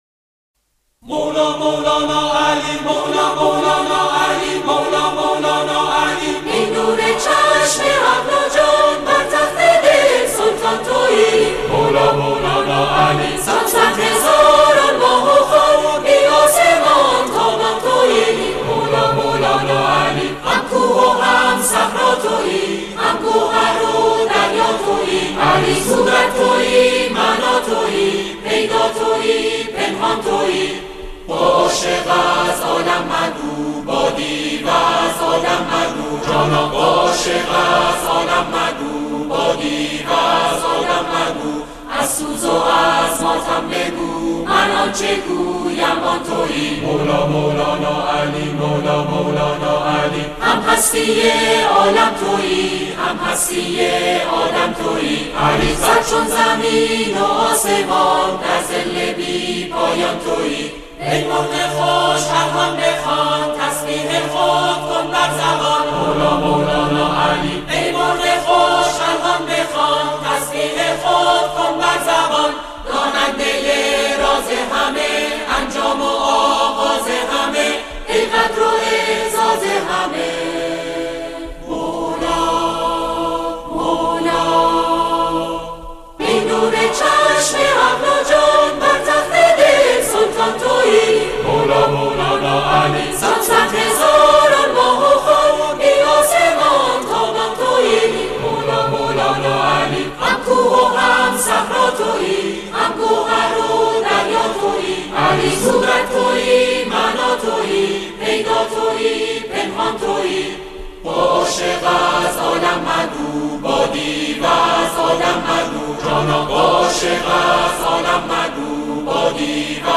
آکاپلا
با اجرای گروهی از جمعخوانان